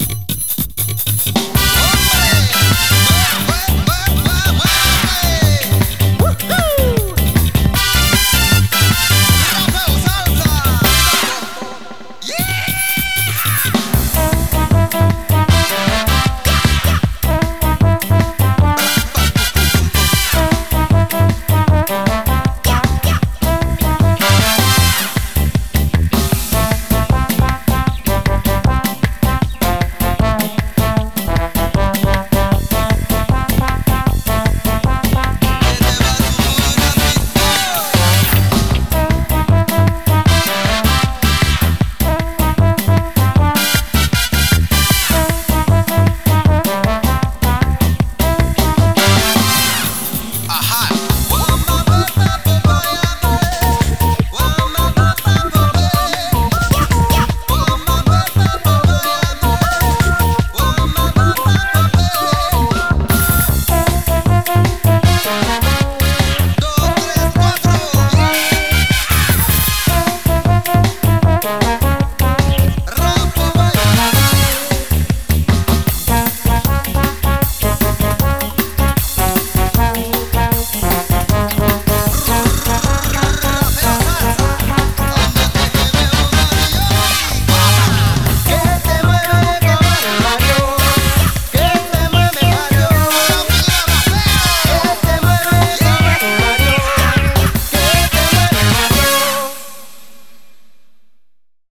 BPM155
Better quality audio.